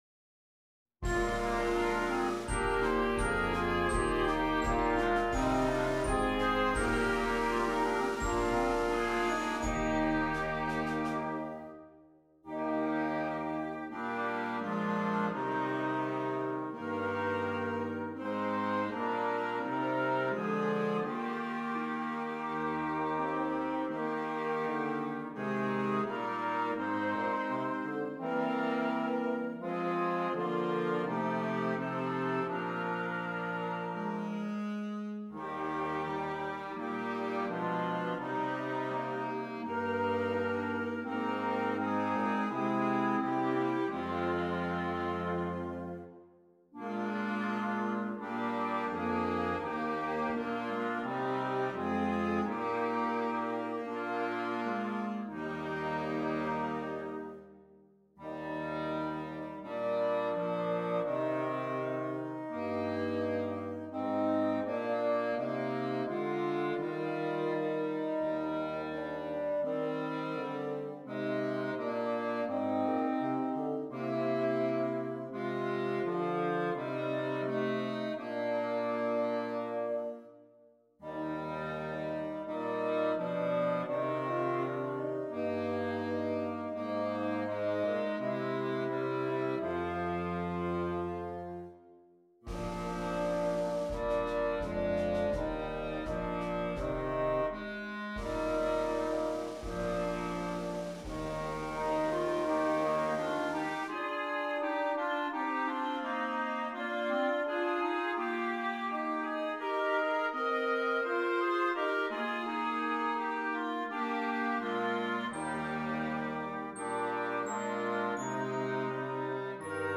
Interchangeable Woodwind Ensemble
PART 1 - Flute, Oboe, Clarinet
PART 3 - Alto Saxophone, F Horn
PART 5 - Bass Clarinet, Bassoon, Baritone Saxophone